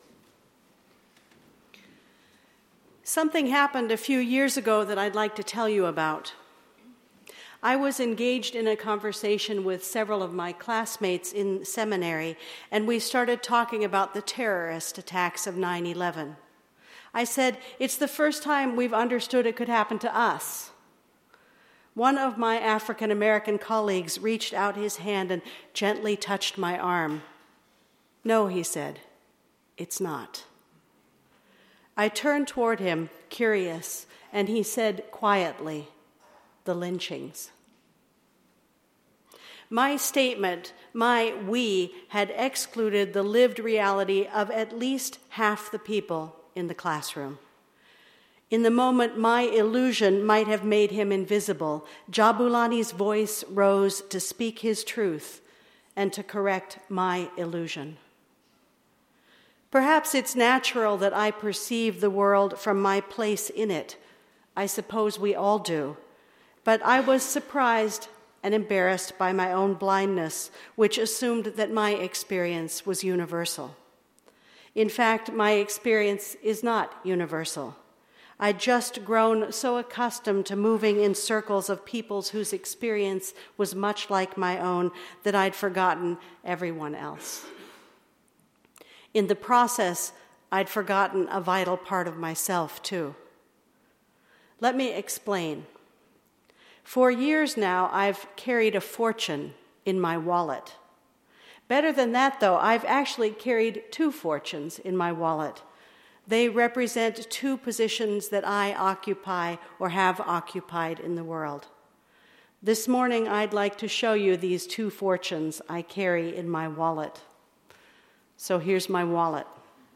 always-a-voice-rises-sermon.mp3